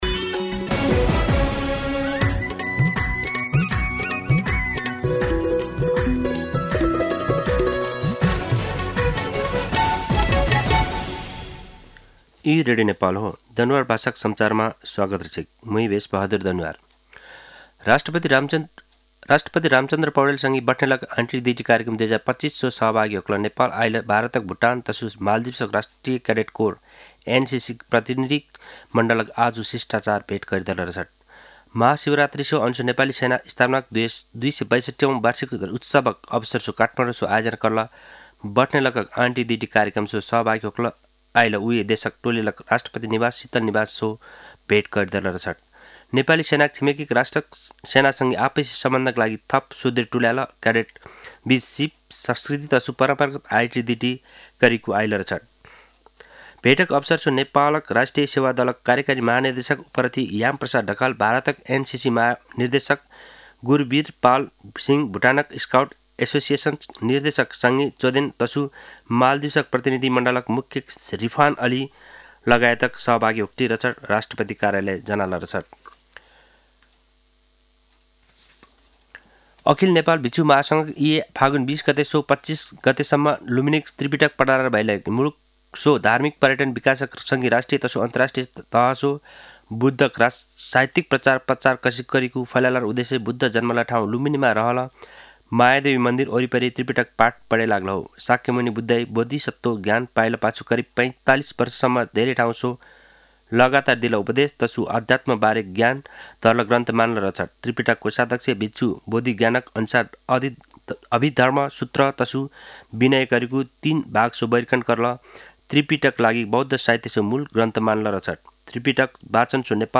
दनुवार भाषामा समाचार : १४ फागुन , २०८१
Danuwar-News-13.mp3